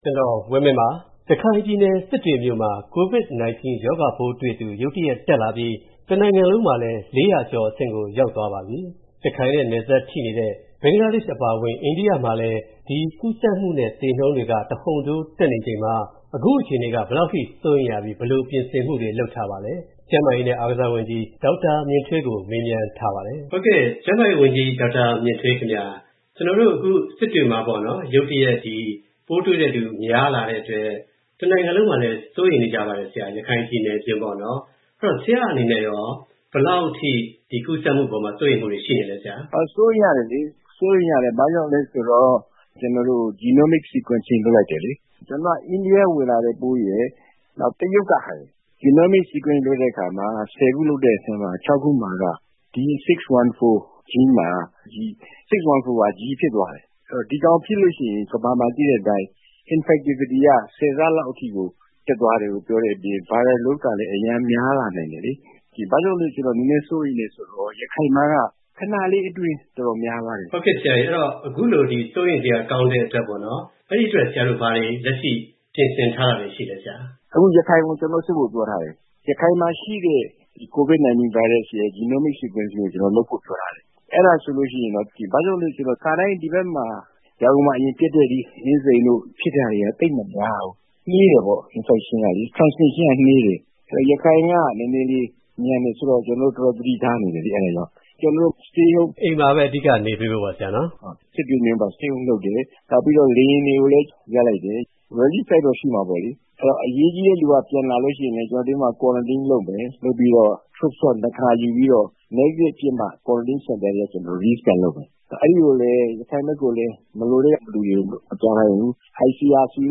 ကျန်းမာရေးနဲ့ အားကစားဝန်ကြီး ဒေါက်တာ မြင့်ထွေးကို